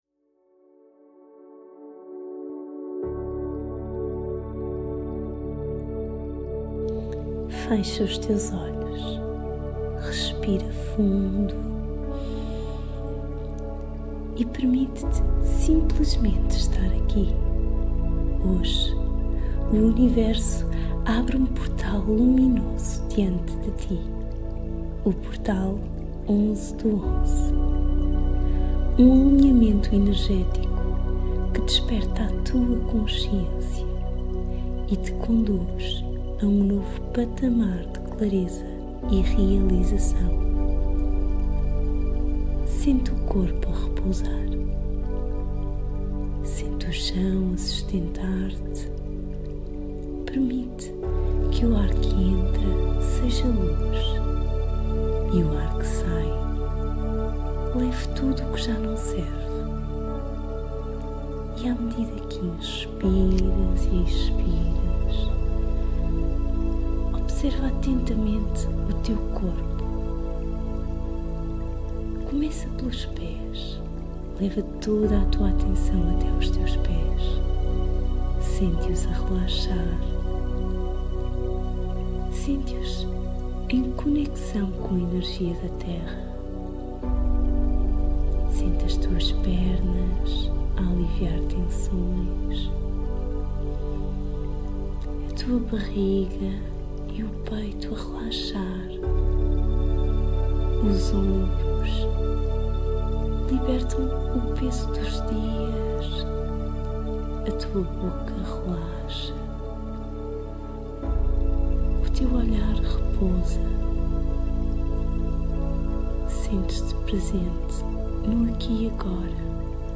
Meditaçao Gratuita